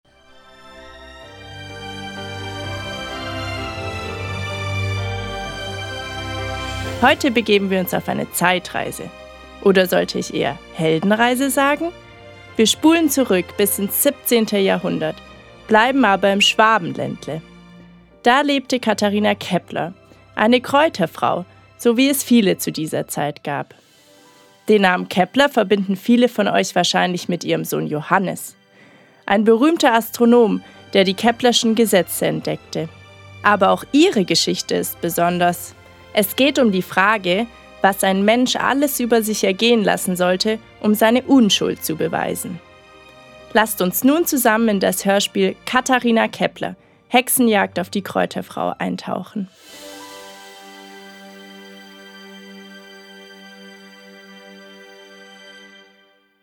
Kinderhörspiel: „Katharina Kepler – Hexenjagd auf die Kräuterfrau“ (581)
01 Scarborough Fair / Canticle – Simon & Garfunkel (1966)